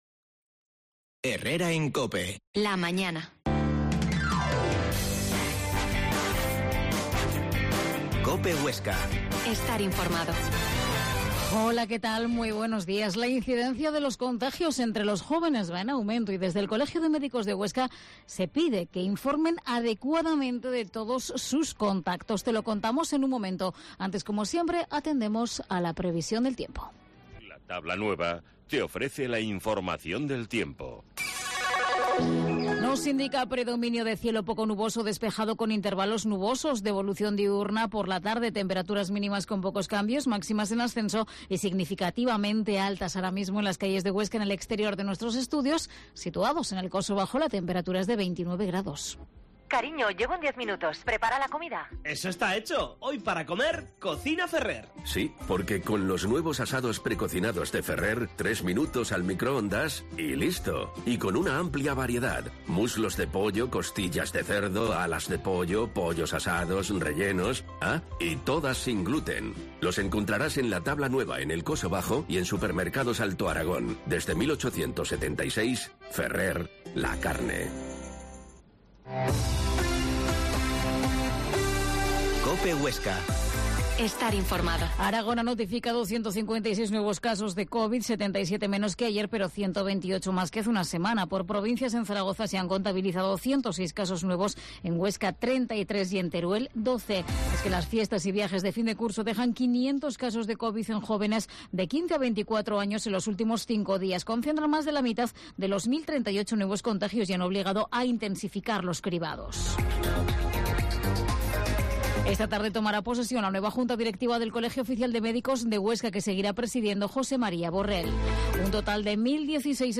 La Mañana en COPE Huesca - Informativo local Herrera en Cope Huesca 12,50h.